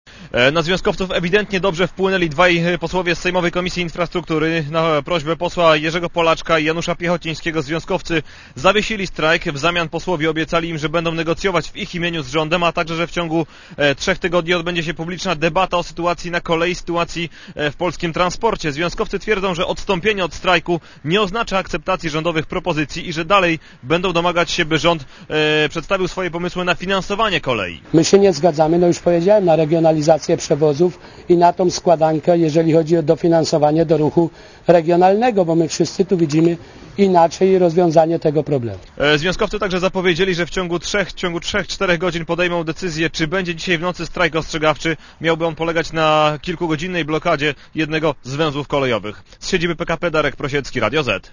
Relacja reportera Radia Zet (200Kb)